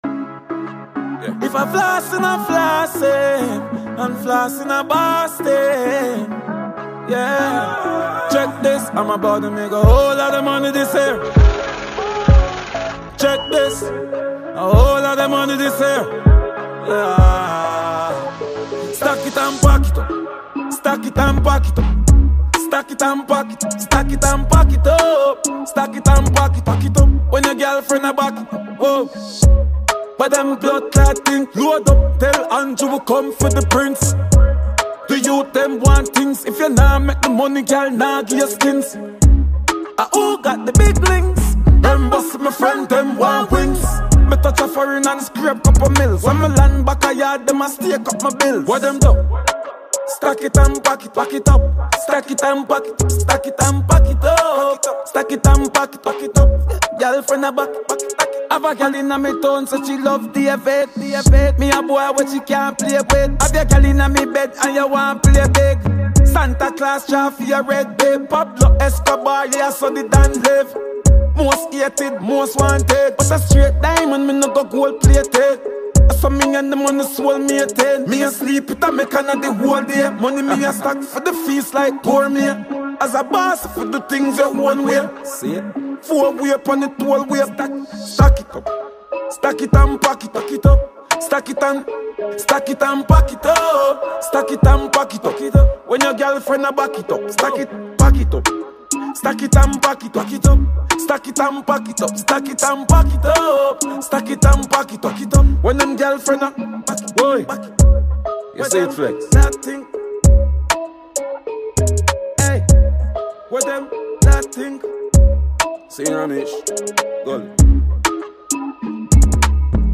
Jamaican reggae-dancehall singer